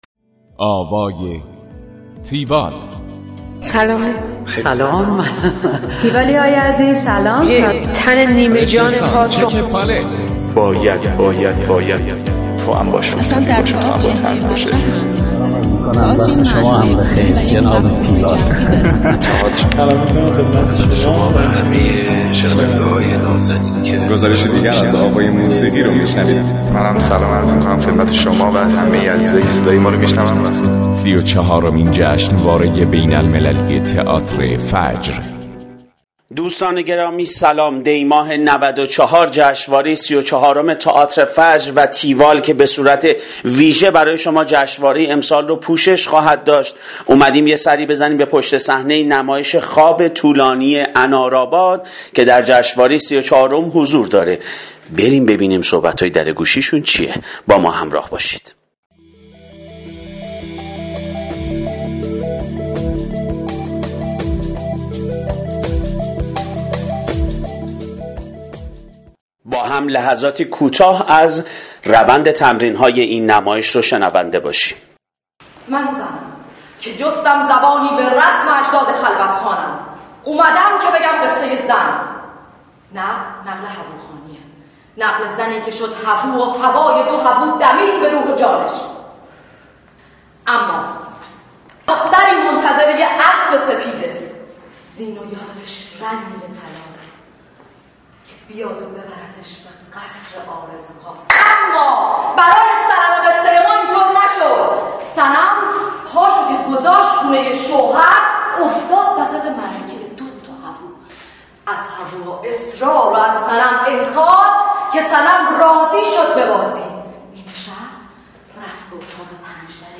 گزارش آوای تیوال از نمایش خواب طولانی انار آباد